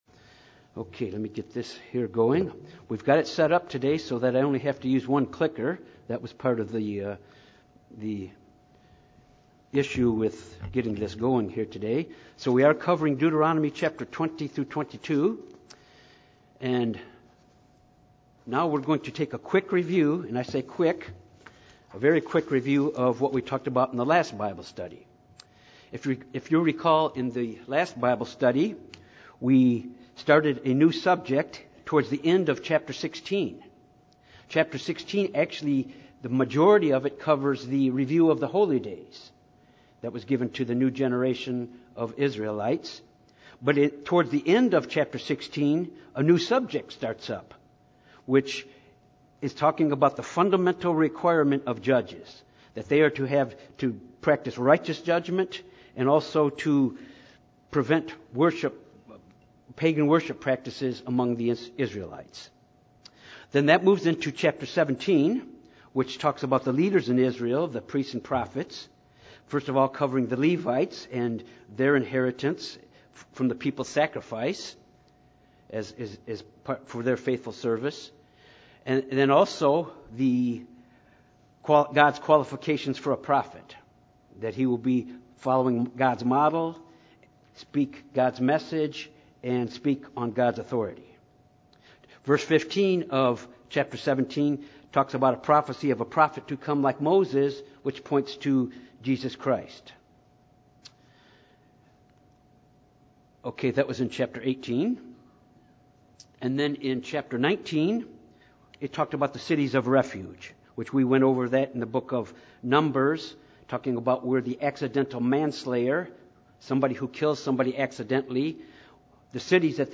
This Bible study covers Deuteronomy chapters 20-22, which is a continuation of Moses’ second sermon to the new generation of the Children of Israel just before they enter into the Promised Land. In these chapters, God gives laws concerning warfare, such as exemptions from military service, military strategies of distant cities not in Canaan. God through Moses also gave instructions concerning unresolved murders, proper bestowal of inheritance rights as well as dealing with unruly sons.